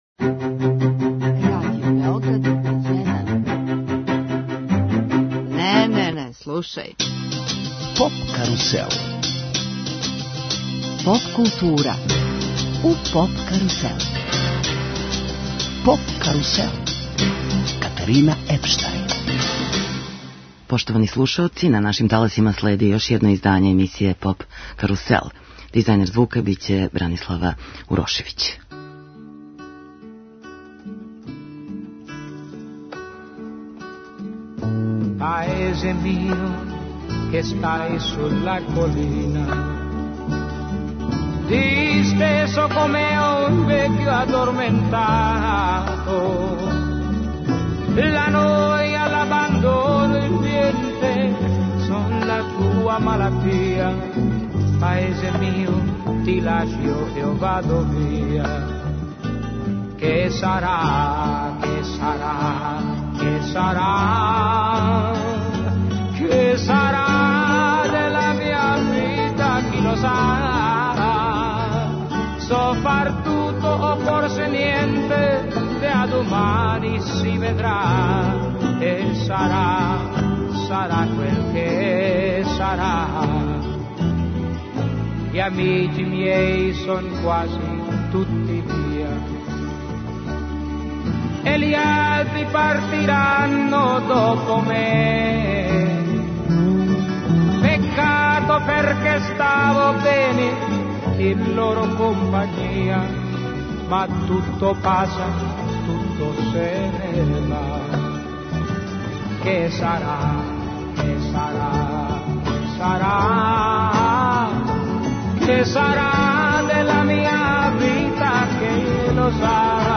Ове године, домаћин је Италија и у Торину у Арени Пало Олимпико, одржаће се две полуфиналне вечери и велико финале. Емисија 'Поп карусел' је посвећена Евросонгу а музиком подсећамо на победнике, највеће хитове и домаће представнике, највећег европског музичког такмичења.